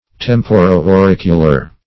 Search Result for " temporo-auricular" : The Collaborative International Dictionary of English v.0.48: Temporo-auricular \Tem`po*ro-au*ric"u*lar\, a. (Anat.) Of or pertaining to both the temple and the ear; as, the temporo-auricular nerve.